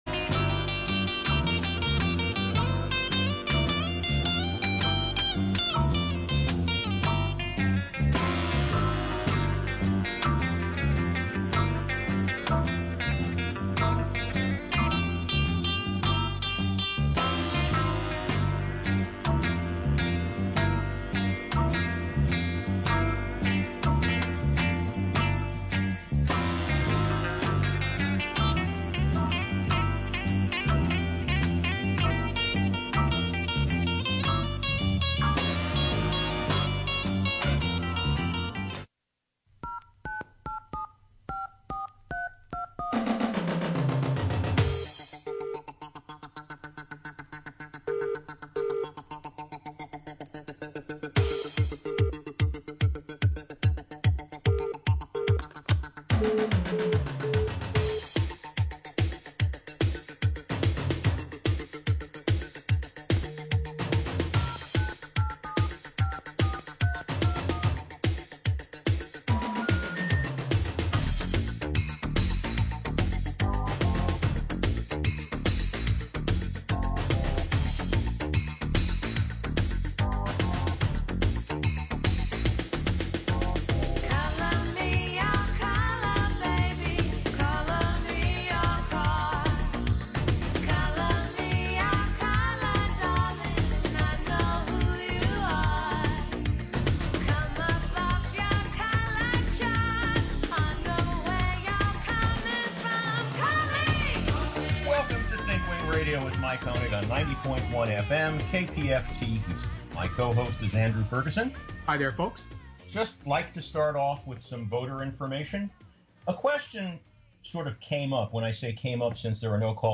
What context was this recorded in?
Due to Covid-19, shows are being prerecorded beginning March 13th and until further notice. We miss our live call-in participants, and look forward to a time we can once again go live.